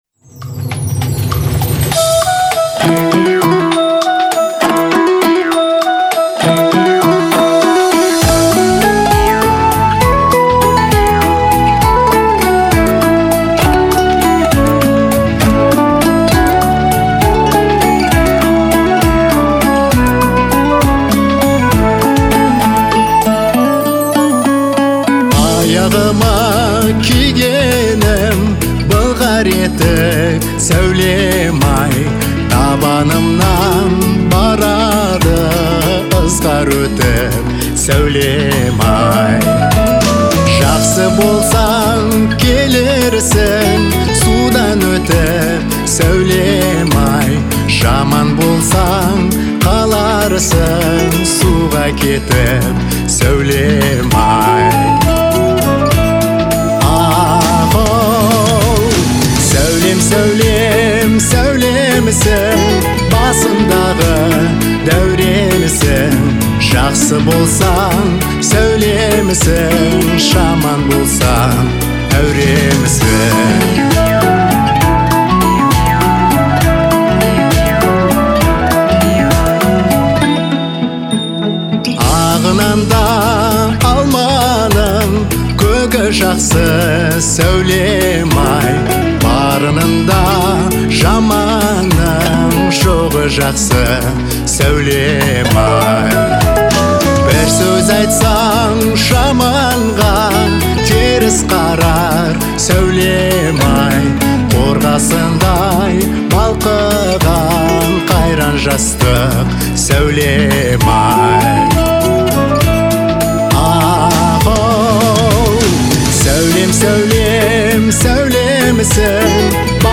1.Халық әні